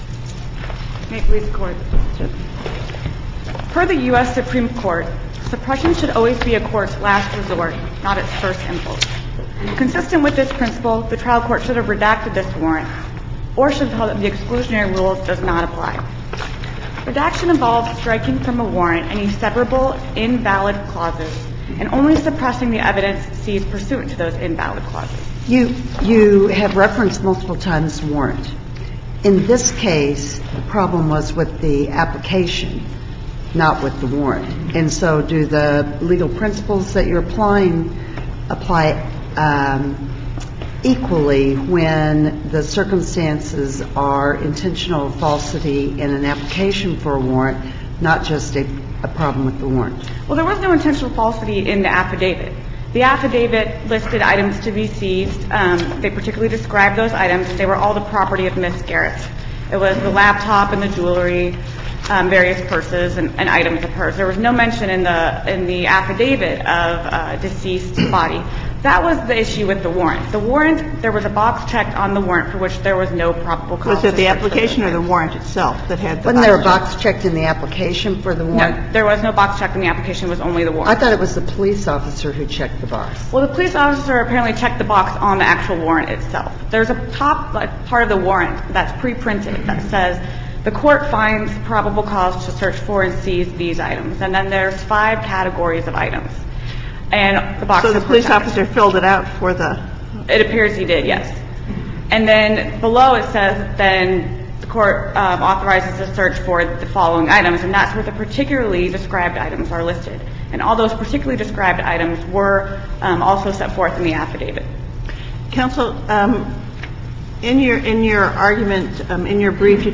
MP3 audio file of arguments in SC95843